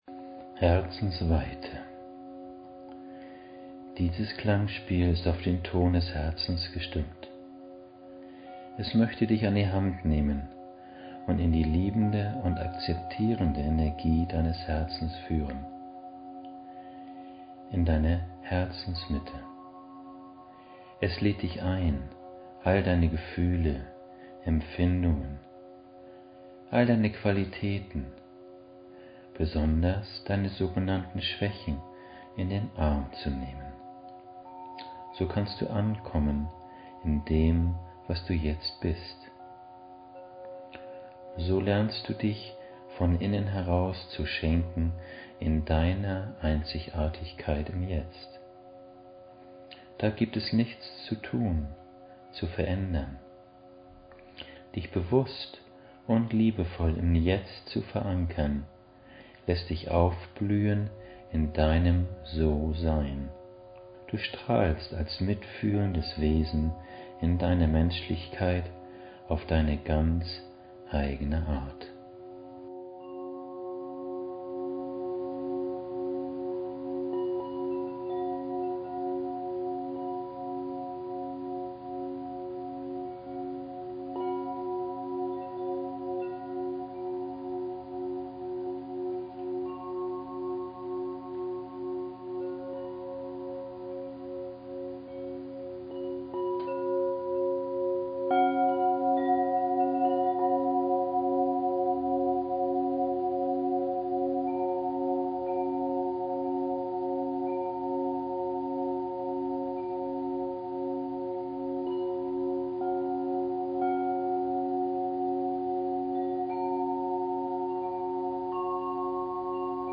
Dieses Klangspiel ist auf den Ton des Herzens gestimmt.